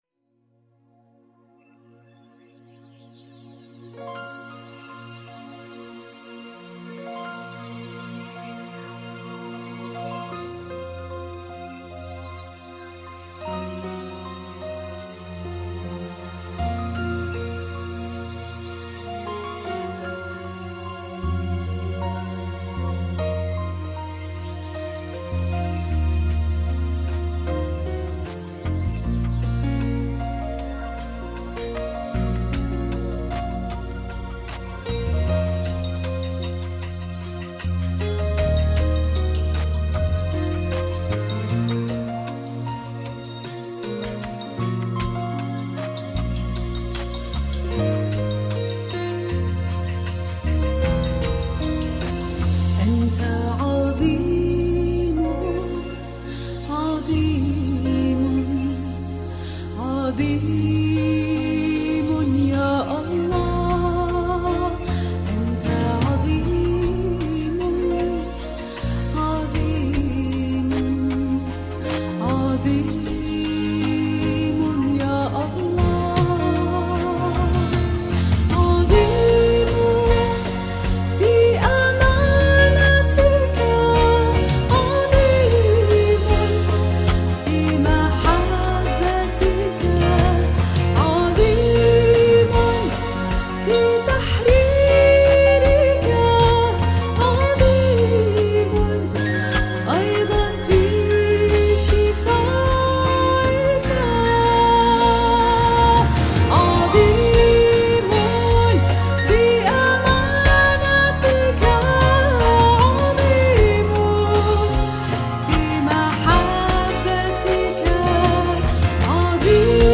ترانيم الدرس 03